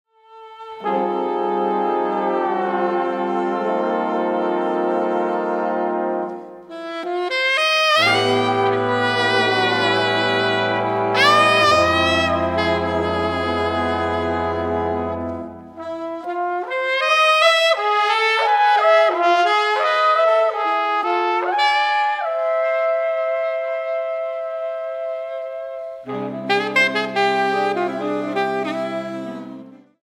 trumpet
Music recorded 2011 at Loft, Cologne